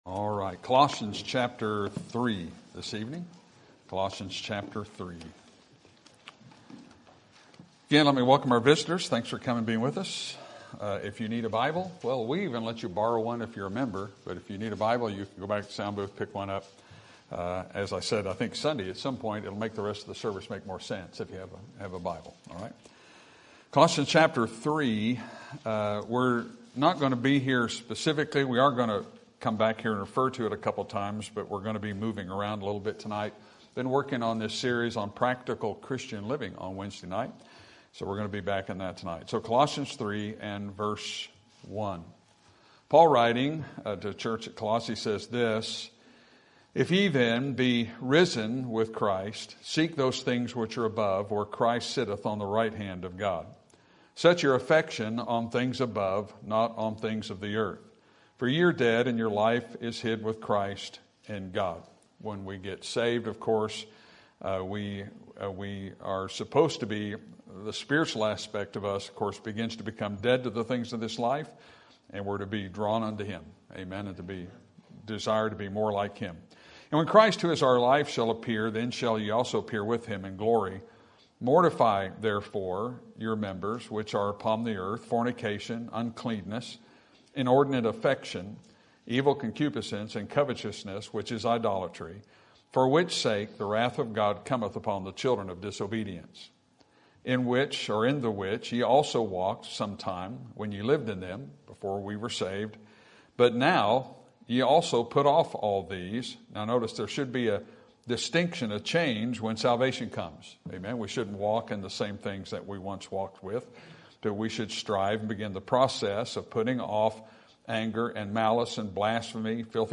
Sermon Topic: Practical Christian Living Sermon Type: Series Sermon Audio: Sermon download: Download (28.64 MB) Sermon Tags: Colossians Affections Above Relationships